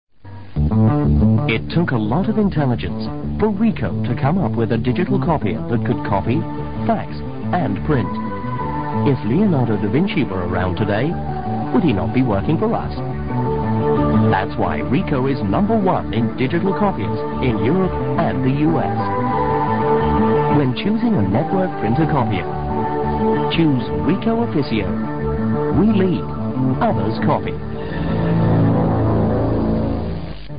听广告学英语:Richon Aficio Copier 理光Aficio复印机